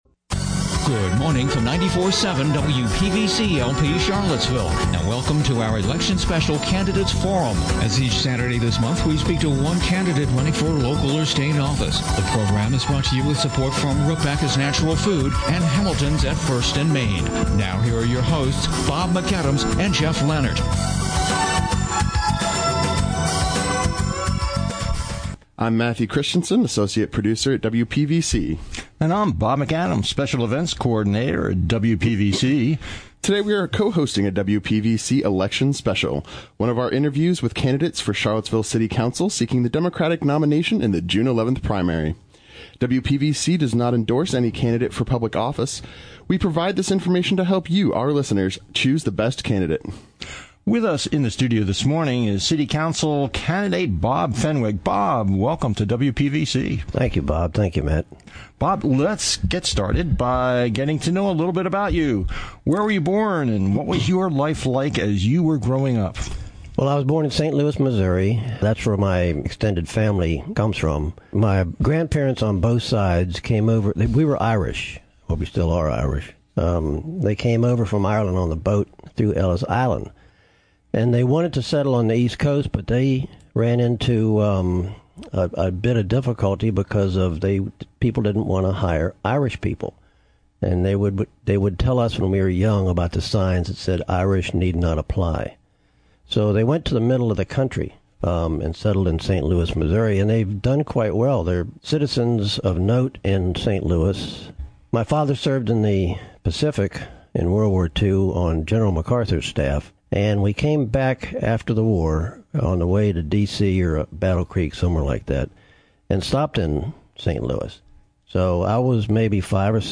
Candidates for Charlottesville City Council speaking before the August 10, 2011 meeting of the Senior Statesmen of Virginia.
Each candidate gave a three minute opening statement before questions were taken from the audience.